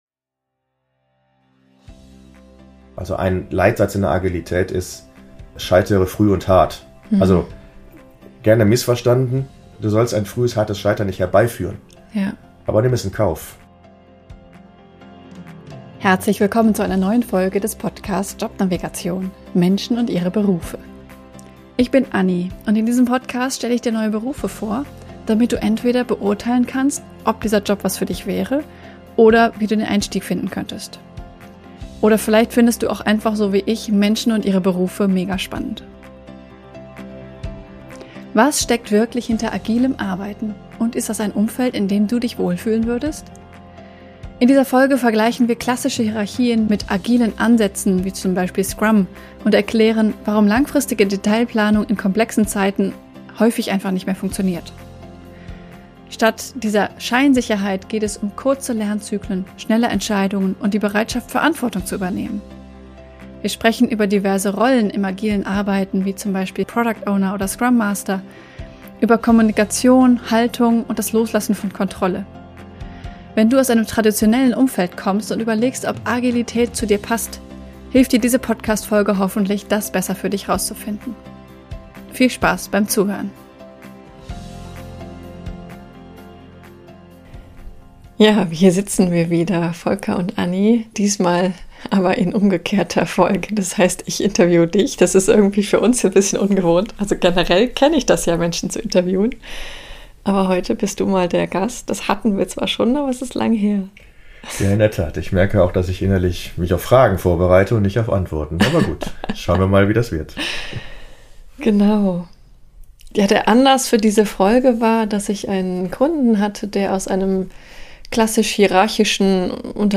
In dieser besonderen Jubiläumsfolge werde ich selbst interviewt: